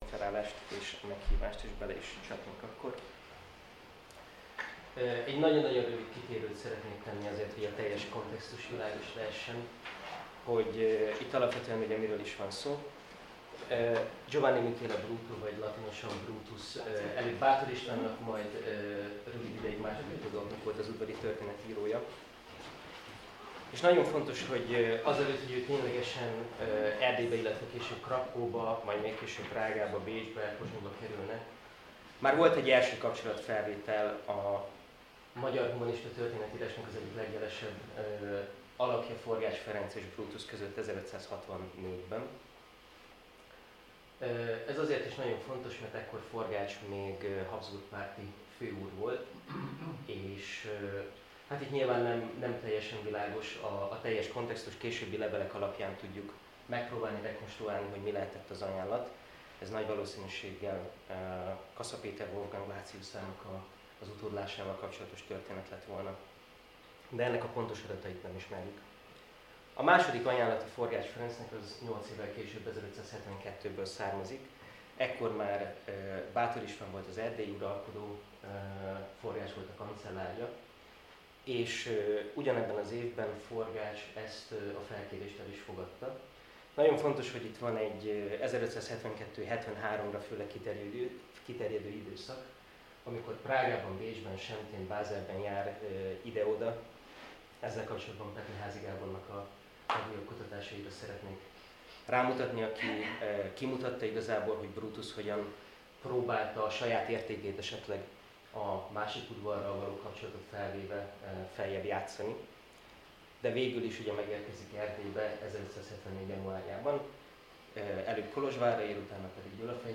BTK ITI Reneszánsz Osztály felolvasóülései
felolvasó